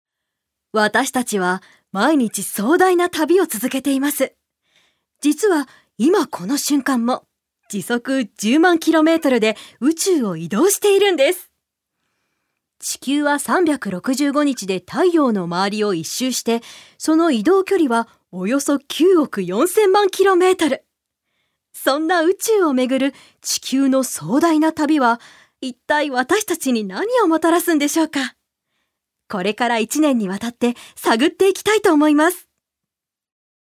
女性タレント
ナレーション４